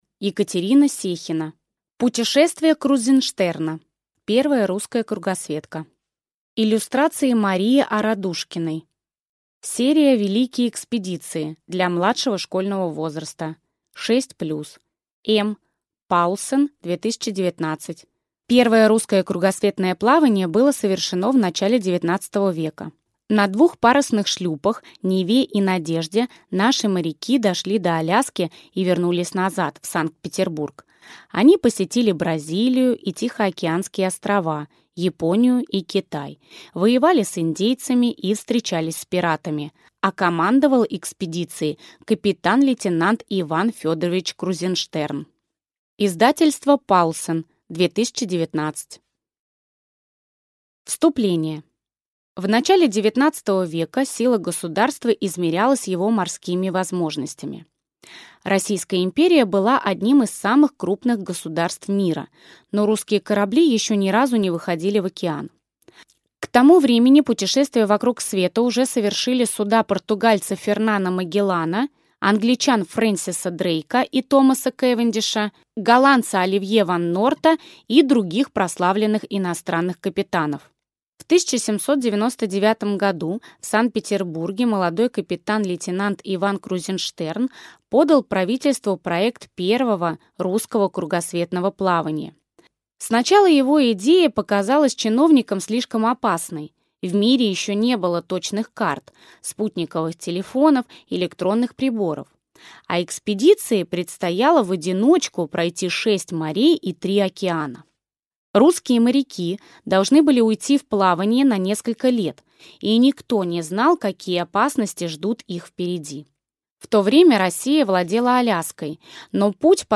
Аудиокнига Путешествие Крузенштерна. Первая русская кругосветка | Библиотека аудиокниг